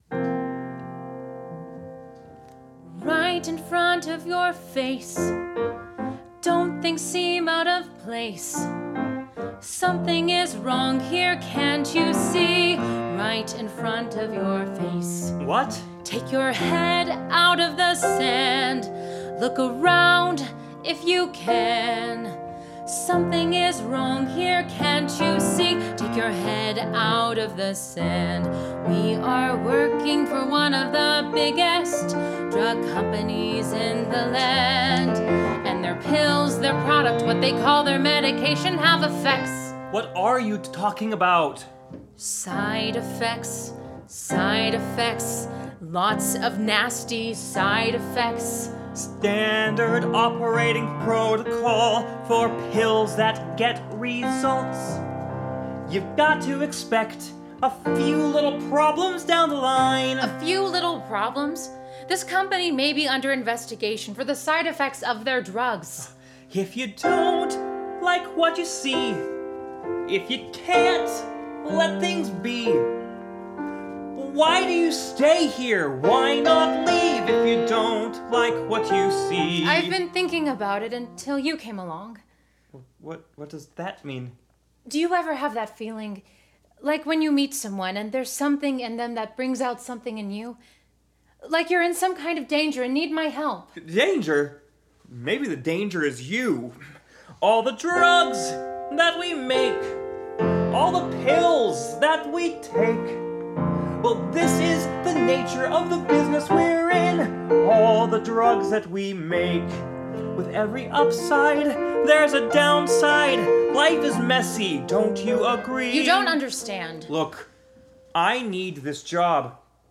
A musical satire for 5 actor/singers and piano